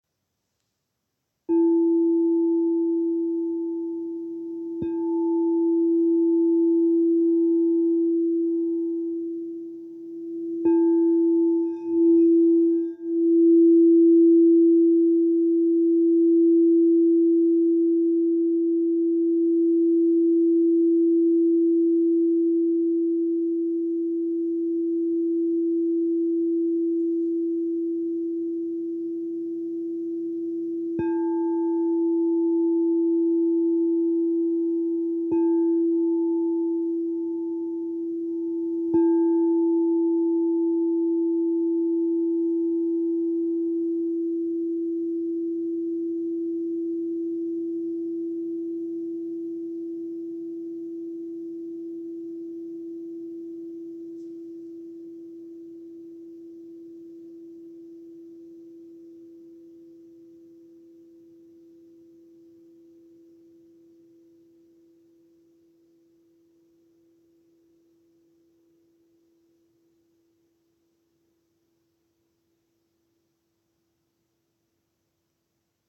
"Zpívající" Křišťálové mísy
Mísa tón D velikost 10" (25,5cm)
Ukázka mísa D
Mísa D-6.m4a